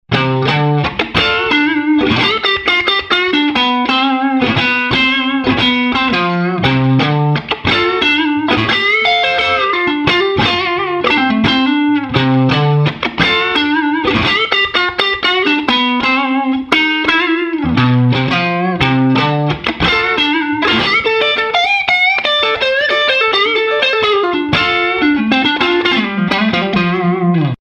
• Falante de Guitarra
• British Style
Tropical Tone é o nosso primeiro falante de 10″ para guitarra voltado para quem procura um falante com bons graves, definição e suporte a potencia, já que o Tropical Tone possui 50 watts rms.
White_Stripe_Crunch1.mp3